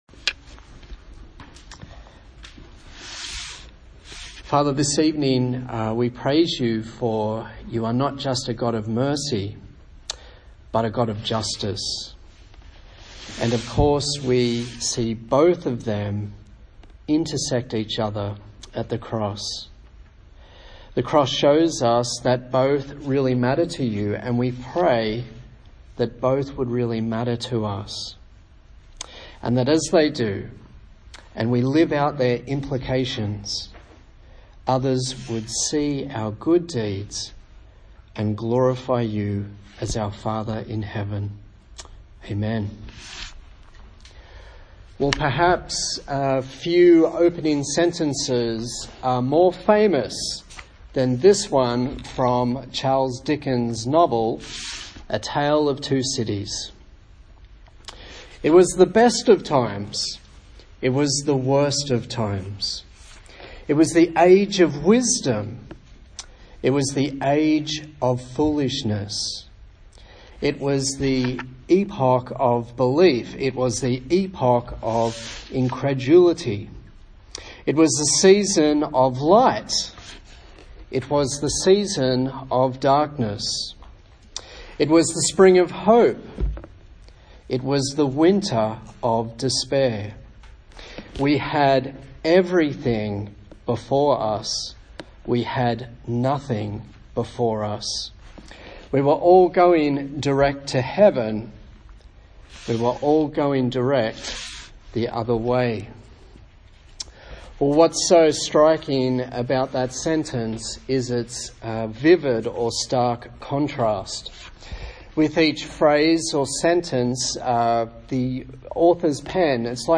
A sermon in the series on the book of Isaiah